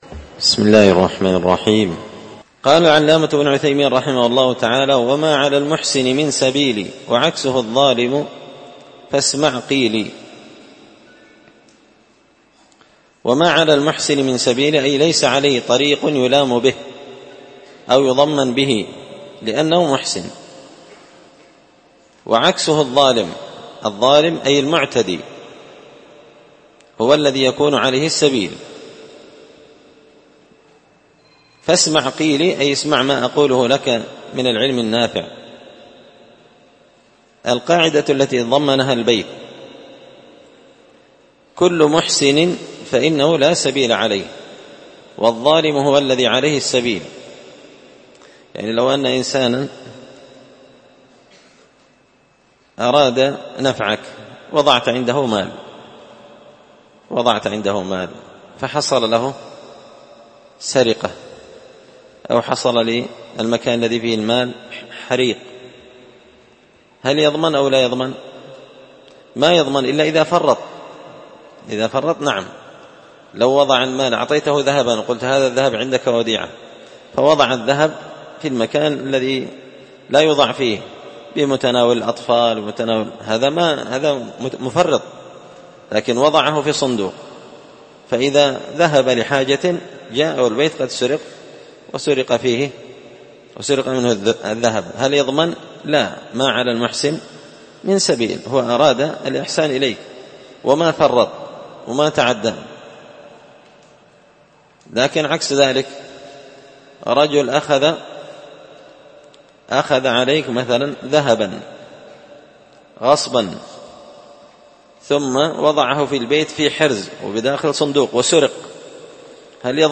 تسهيل الوصول إلى فهم منظومة القواعد والأصول ـ الدرس 34
مسجد الفرقان